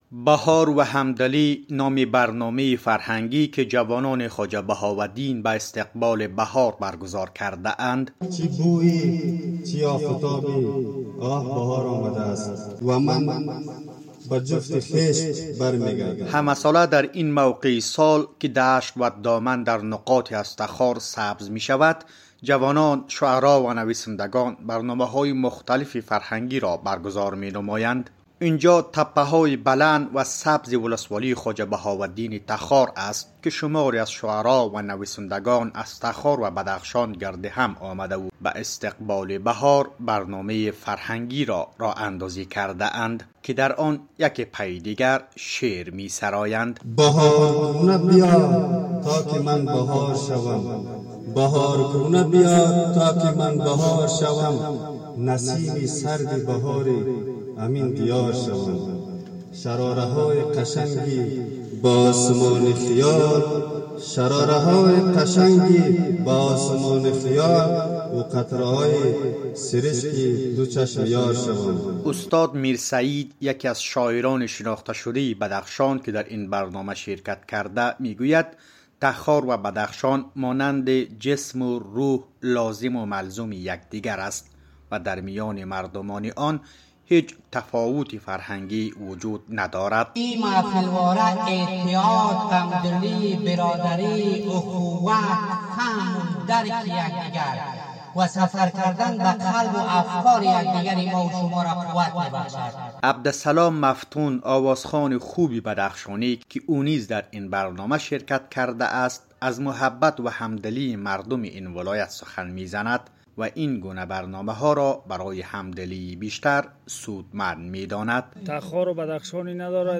محفل شعر بهاری در ولسوالی خواجه بهاءالدین ولایت تخار